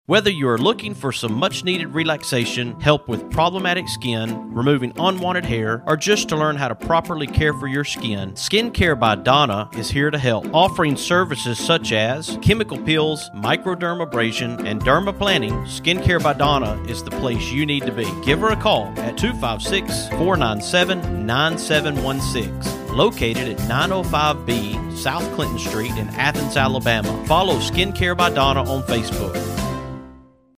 Here is a commercial I cut as part of her sponsorship.